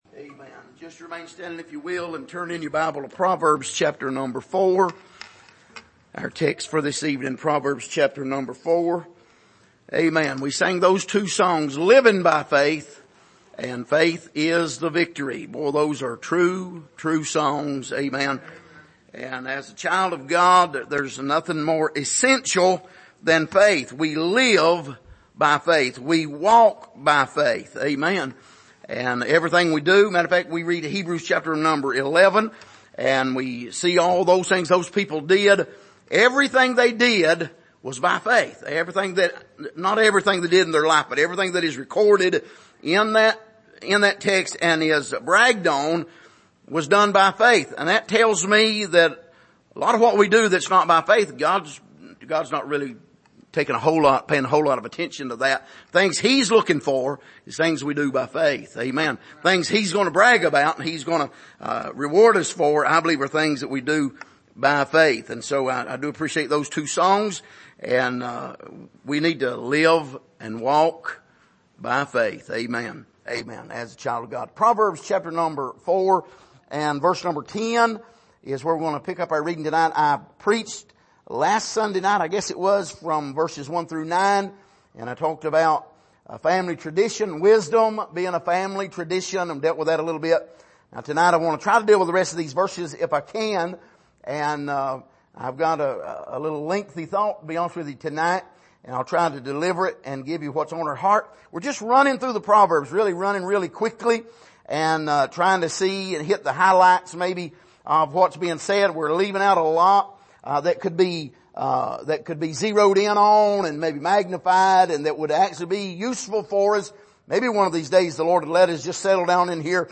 Passage: Proverbs 10:10-27 Service: Sunday Evening